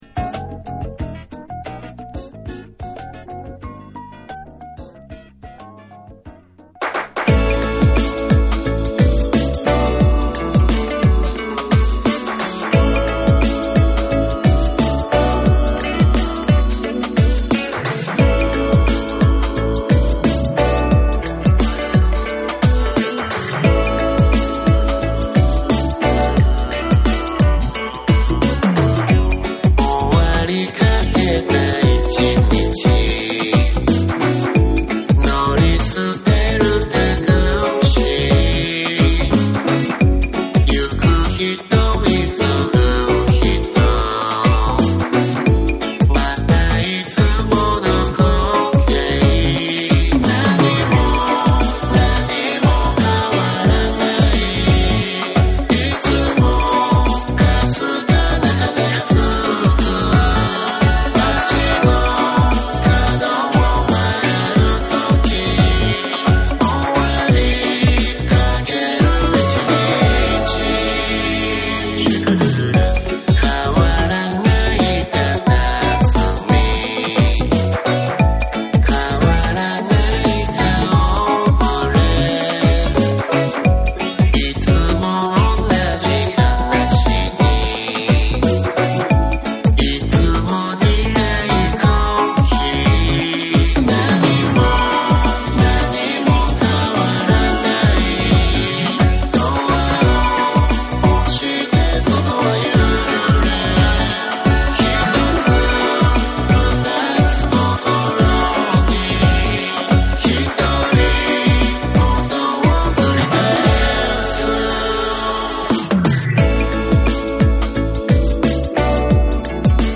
SOUL & FUNK & JAZZ & etc / MIX CD